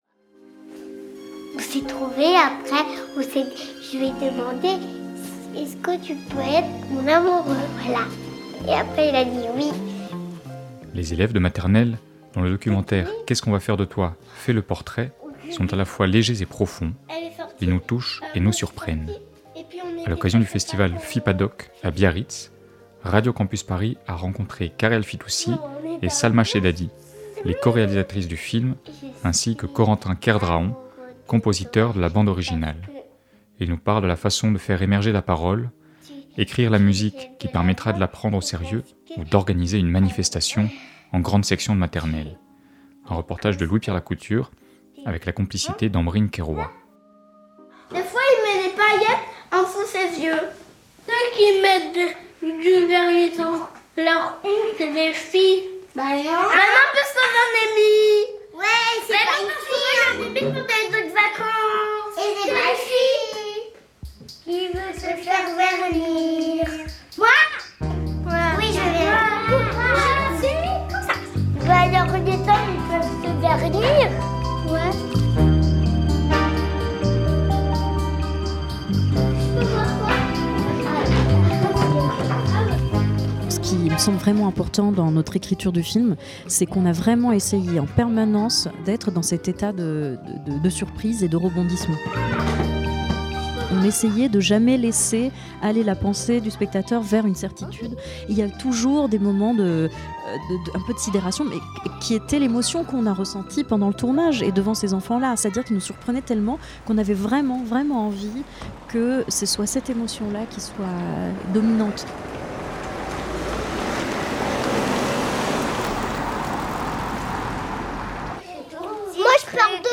Magazine
En avril, La Matinale de 19h propose une série d'interviews enregistrées lors du FIPADOC 2026